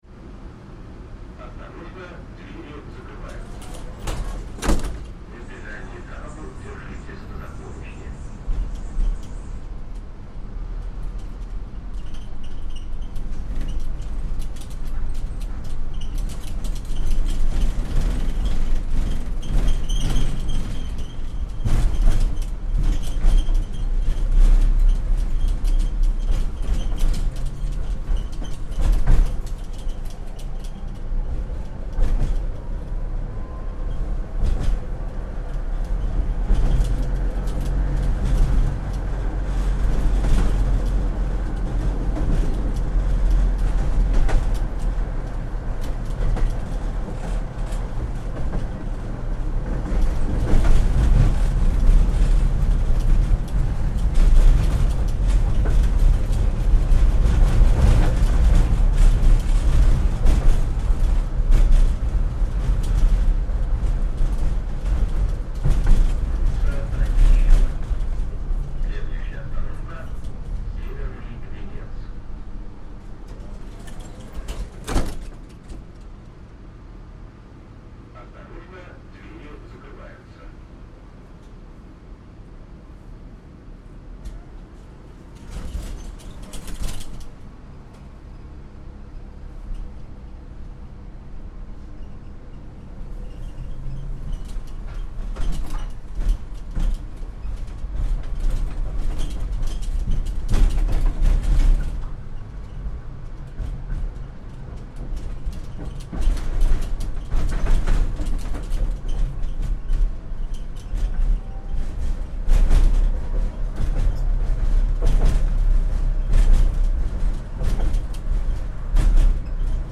Old streetcar, Russia